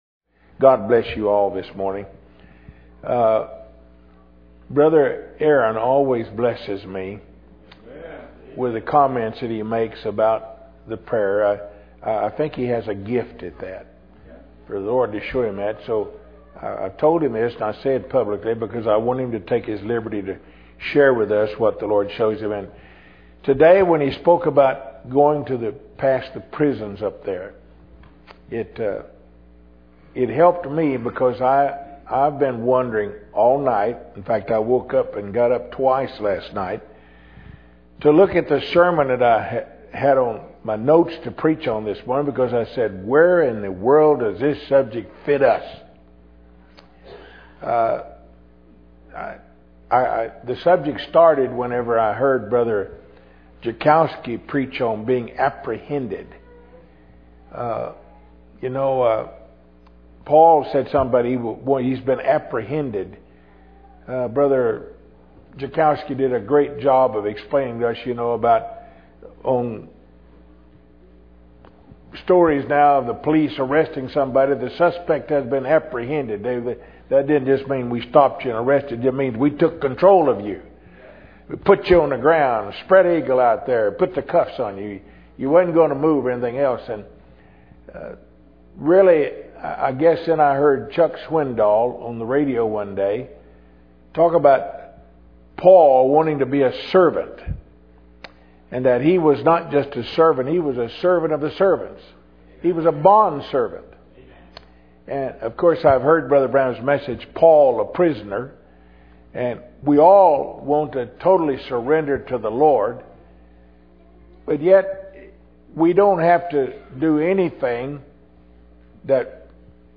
Tucson Tabernacle, church in Tucson, Arizona